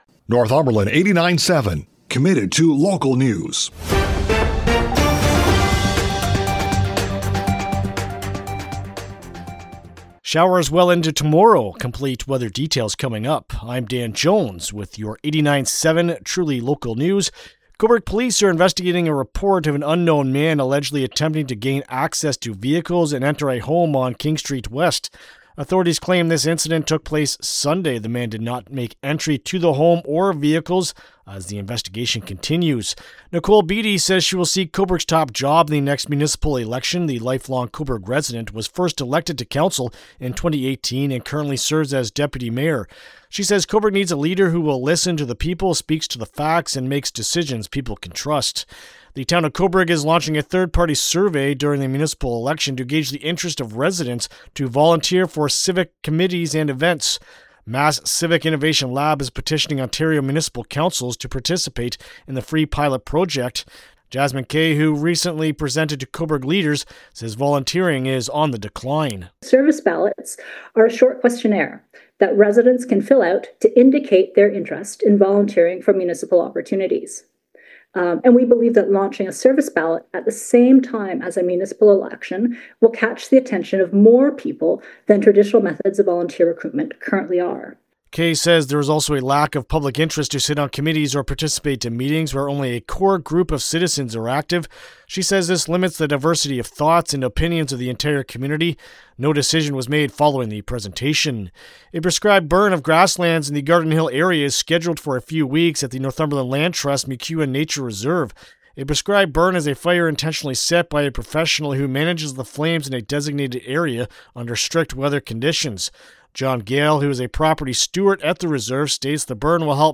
Northumberland 89.7 FM